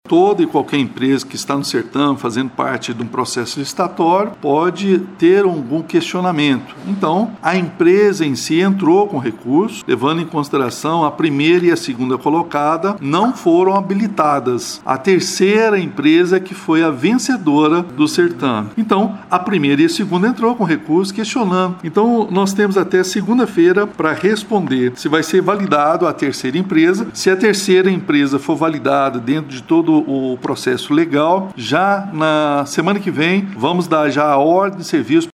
Quem fala sobre a situação é o prefeito Elias Diniz, explicando que a demora se deve a trâmites do processo licitatório.